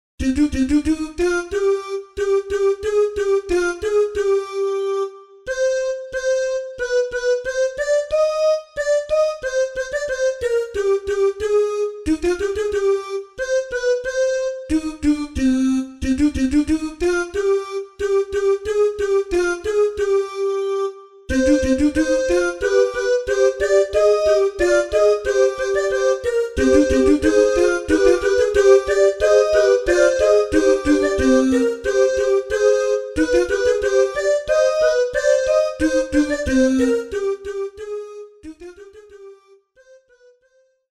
RÉPERTOIRE  ENFANTS
CANONS